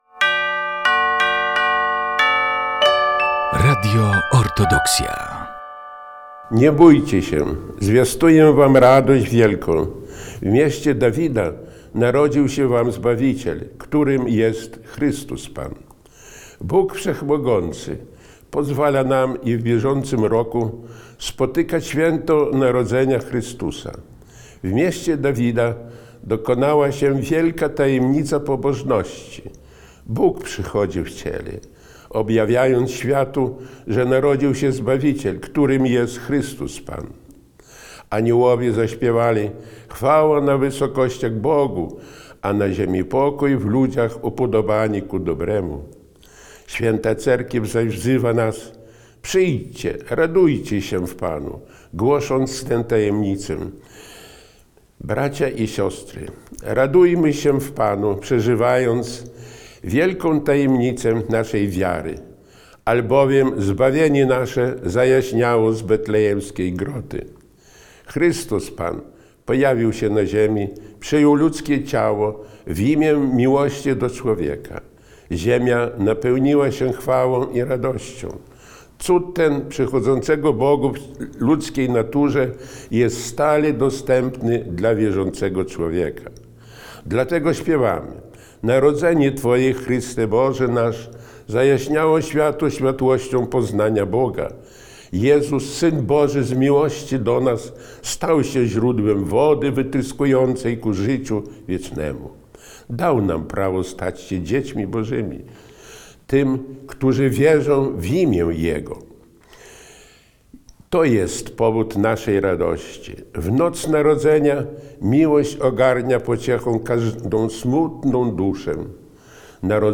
Orędzie Bożonarodzeniowe Jego Eminencji Metropolity Sawy